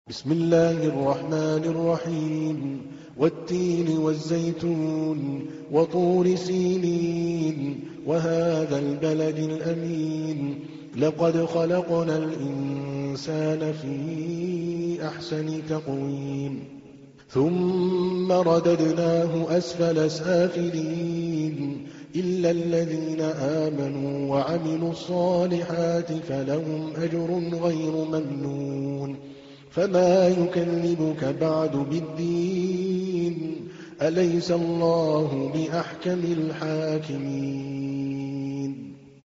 تحميل : 95. سورة التين / القارئ عادل الكلباني / القرآن الكريم / موقع يا حسين